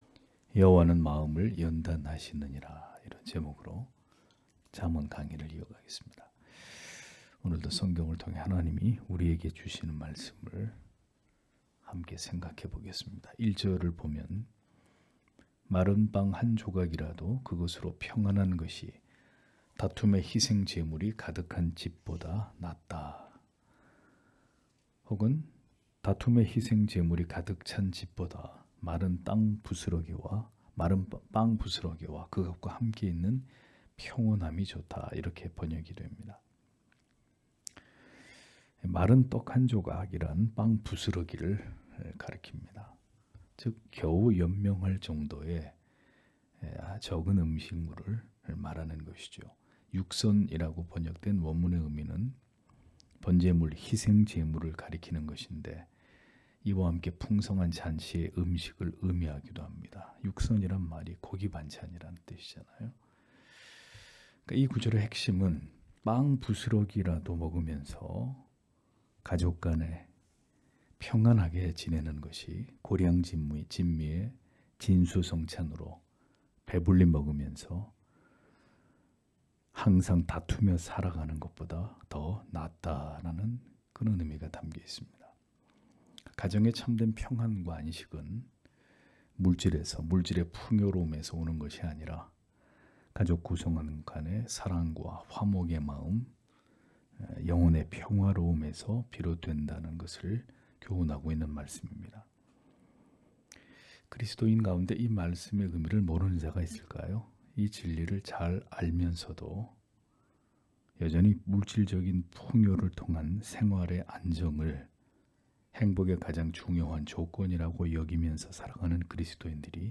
수요기도회 - [잠언 강해 98] 여호와는 마음을 연단하시느니라 (잠 17장 1-4절)